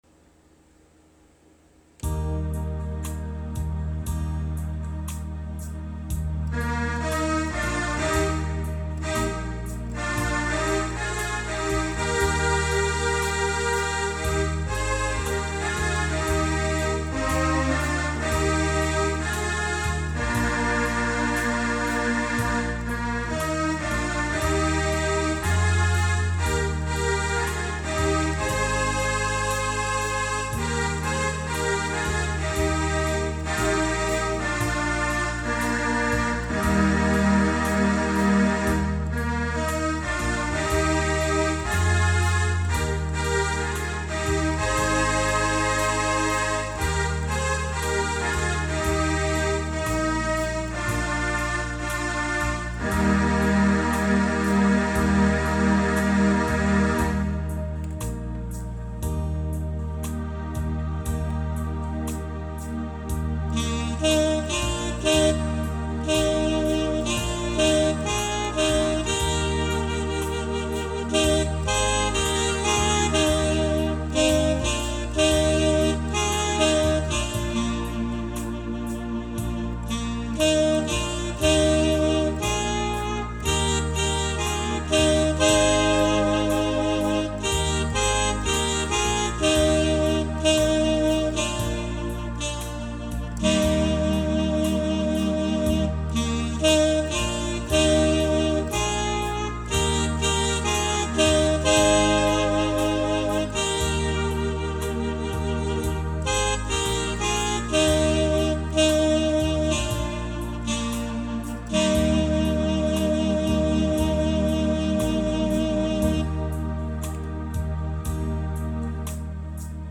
Αποδιδομένηνμέ Ακορντεόν, μέ Σαξόφωνο καί μέ Στερεοφωνικά Ὀρχηστρικά Β ι ο λ ι ά·
Ἠχογράφησα δέ τοῦτο εις αργότερον-ευληπτότερον ακροαματικόν Ρυθμόν, πρός εὐκολοτέραν κατανόησιν καί ΕΦΑΡΜΟΓΗΝ τῶν Στίχων στή Μουσική.